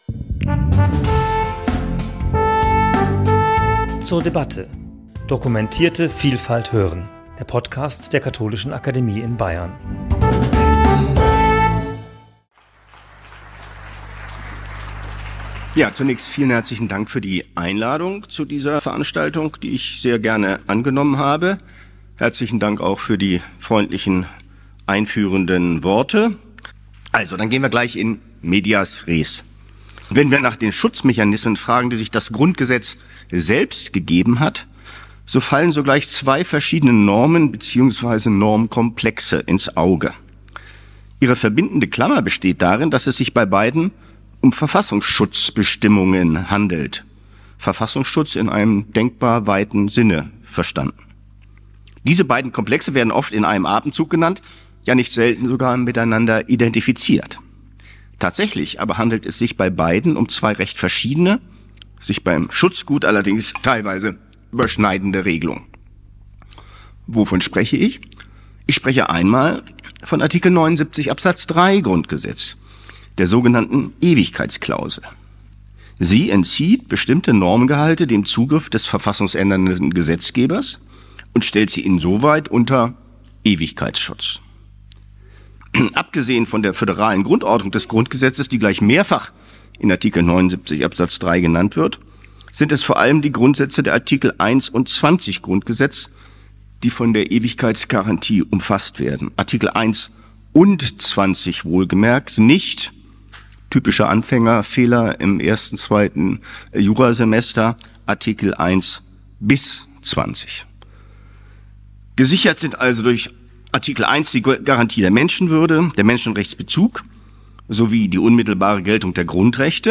Horst Dreier untersuchte die verfassungsrechtlichen Schutzmechanismen und die Widerstandsfähigkeit des Grundgesetzes. Er sah bei der Veranstaltung am 13. Mai 2024 aber auch die Demokratie gleichermaßen durch rechte und linke Extremisten wie auch Islamisten bedroht.
Zunächst stellte der Jurist und Rechtsphilosoph Horst Dreier die Wehrhaftigkeit des Grundgesetzes in den Mittelpunkt.